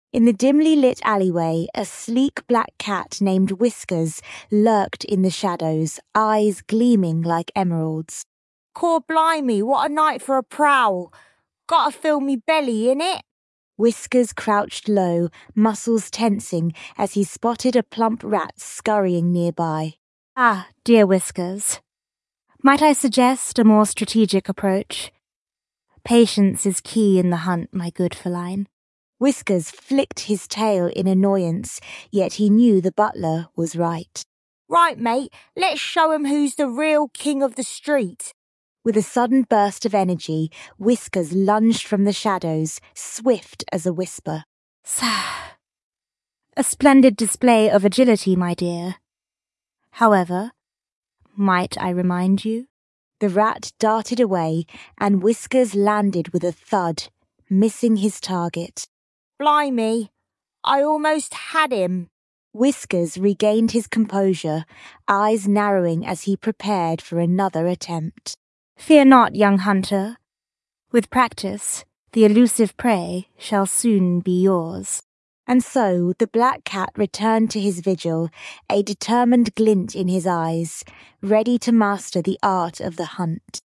This time, the tool cooked up a 90-second story about a person narrating a cat looking for prey. It had both the narrator’s voice (in American accent) and the cat’s voice (yes, the cat speaks in this story, expressing its hunger) in a British accent. Both synced up perfectly and sounded like a collaboration rather than a flat AI voice.